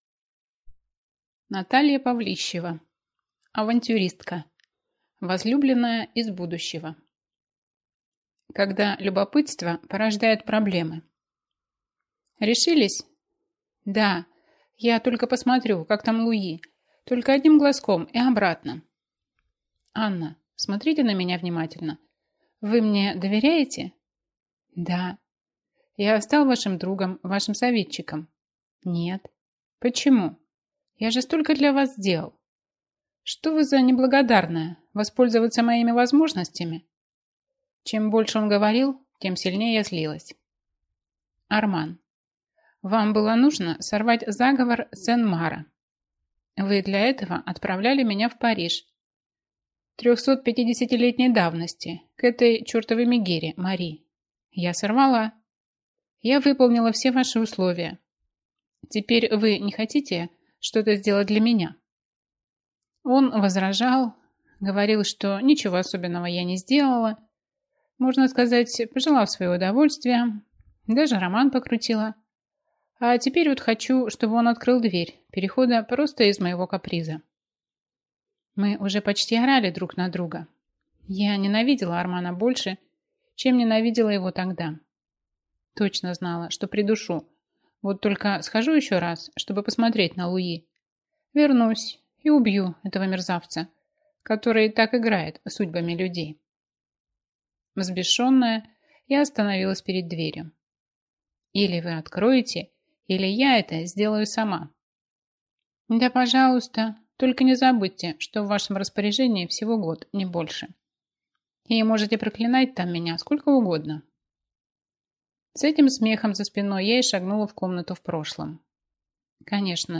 Аудиокнига Авантюристка. Возлюбленная из будущего | Библиотека аудиокниг